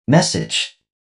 男性の声で「message」と話します。
「message」男性の声 着信音